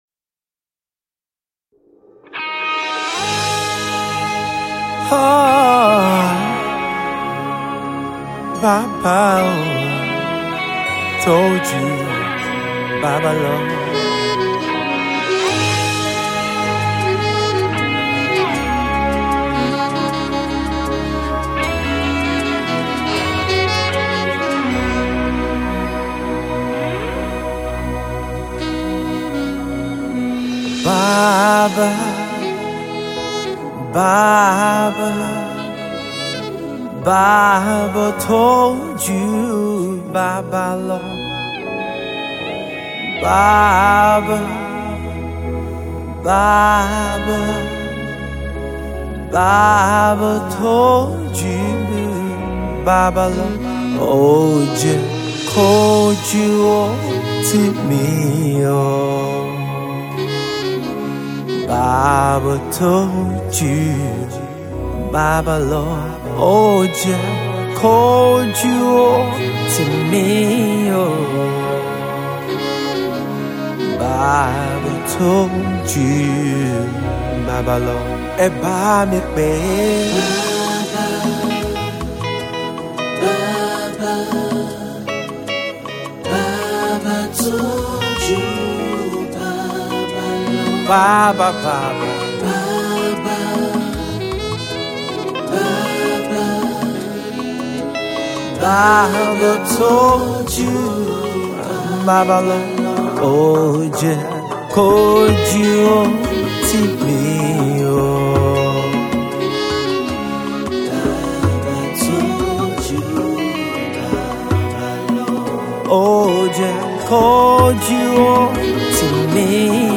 US-based worship singer and songwriter
is a soulful and distinct contemporary highlife song